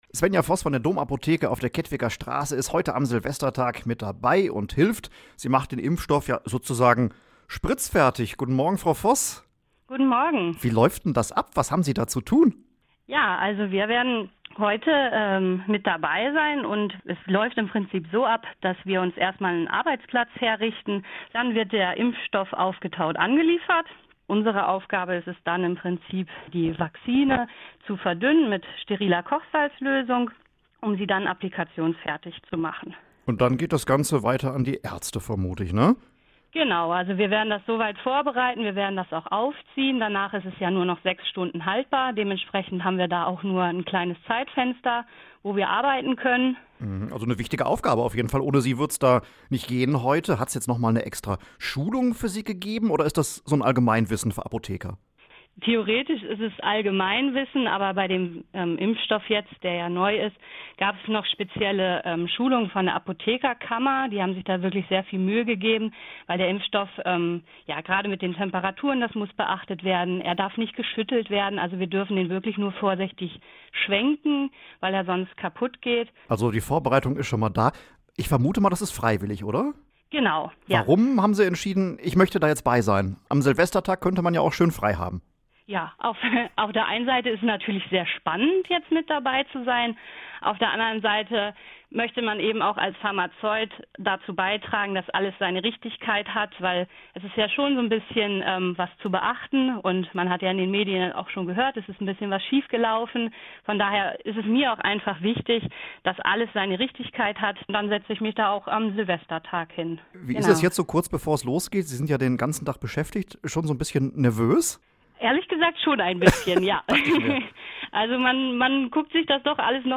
Wir haben mit einer Apothekerin gesprochen, die den Impfstoff spritzfertig macht.